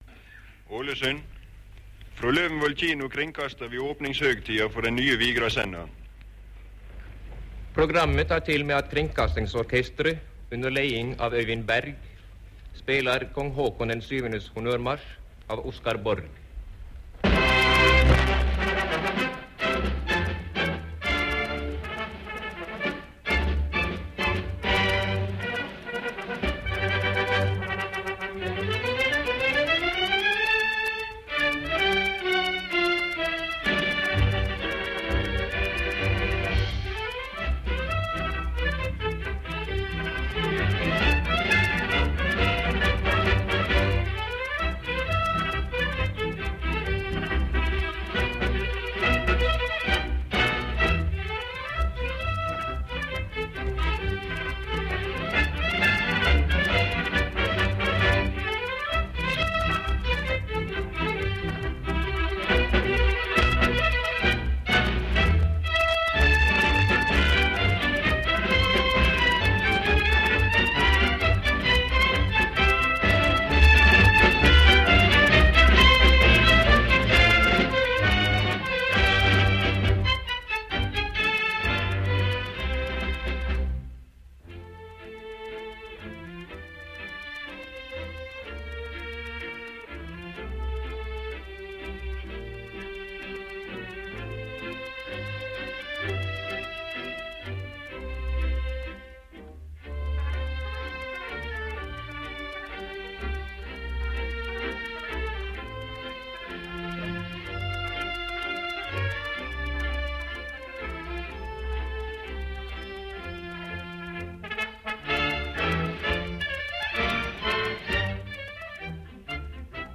Sending frå nyopninga etter krigen.